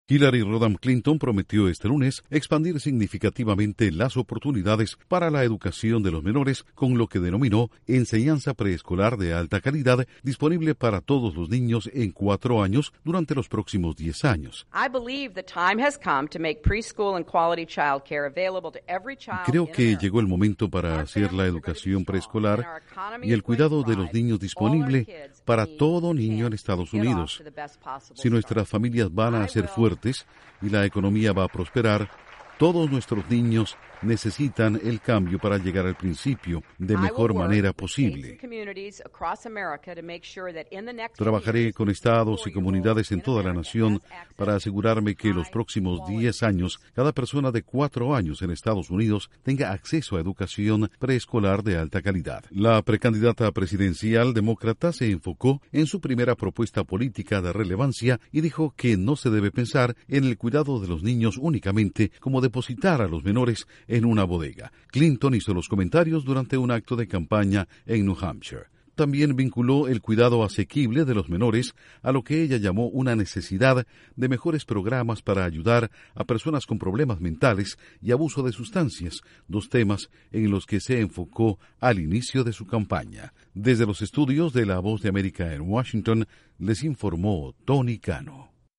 La precandidata presidencial demócrata Hillary Clinton promete ayuda a preescolares si llega a la Casa Blanca. Informa desde los estudios de la Voz de América en Washington